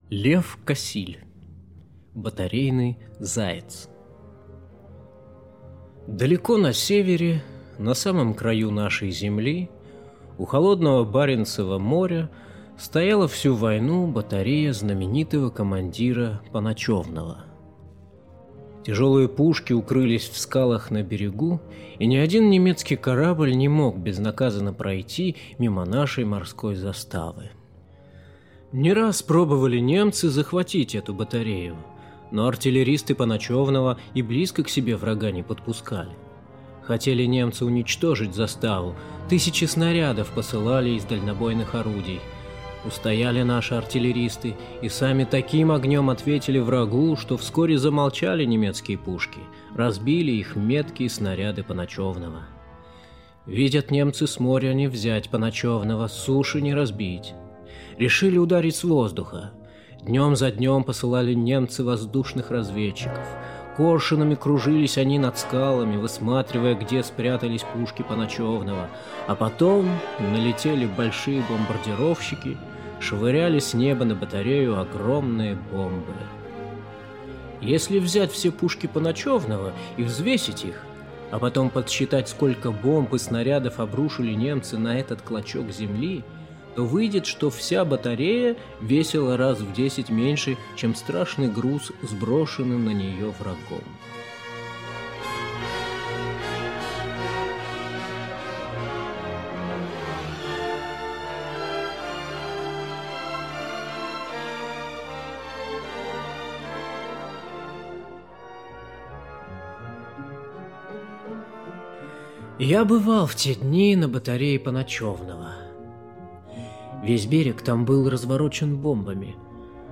Аудиорассказ «Батарейный заяц»